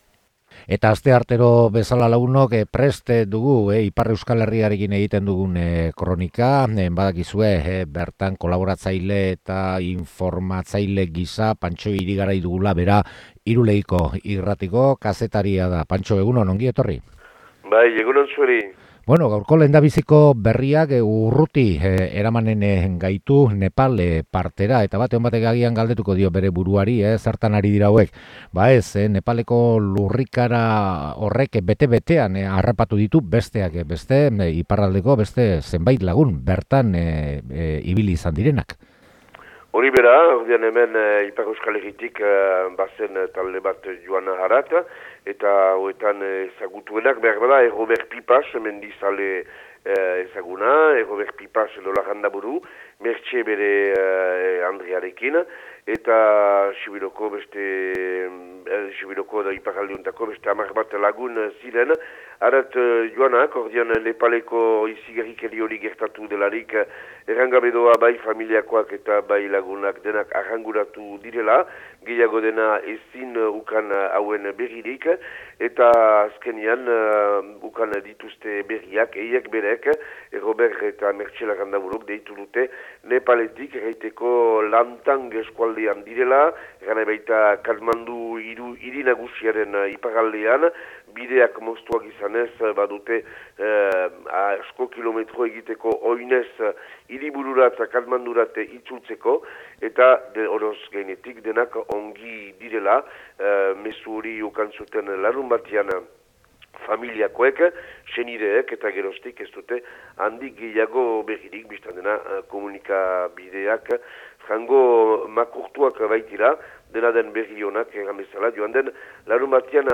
Ahots urratua du